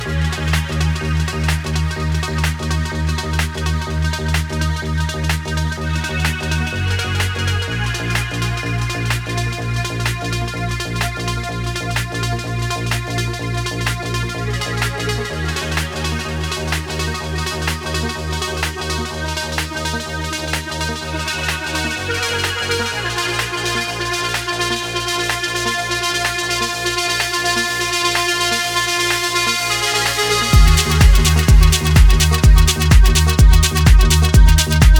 Жанр: Танцевальные / Техно
Techno, Dance